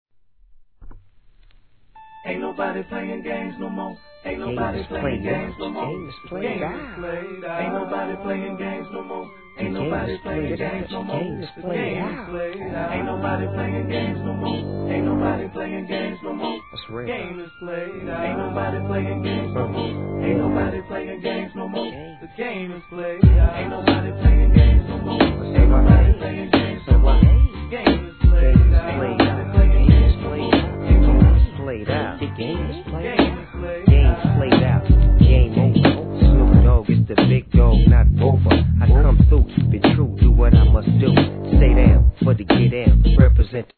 1. G-RAP/WEST COAST/SOUTH